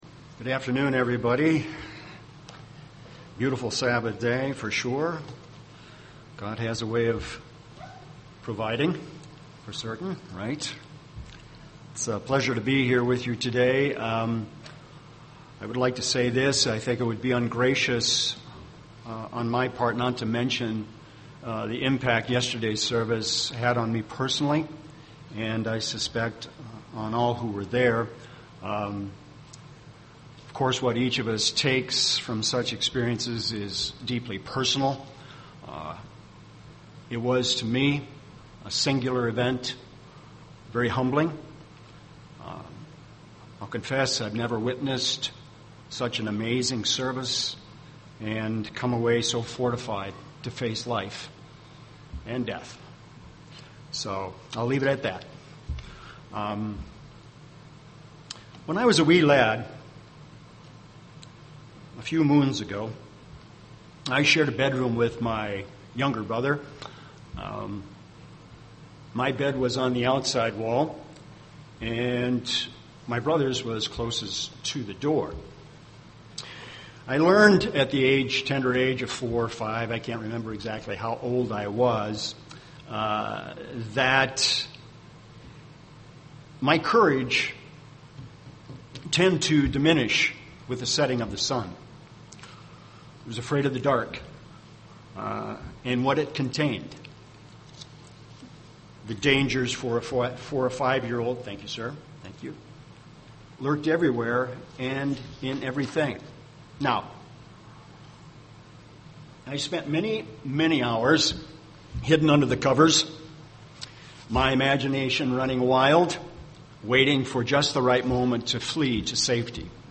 (Mic. 4:4) UCG Sermon Fear Studying the bible?